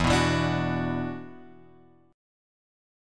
E7sus2 chord {0 2 2 x 3 2} chord